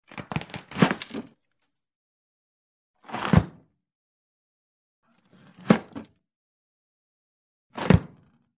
Freezer Door; Open / Close 2; Freezer Door Open / Close ( Twice ) With Some Interior Freezer Movement. Close Perspective. Kitchen.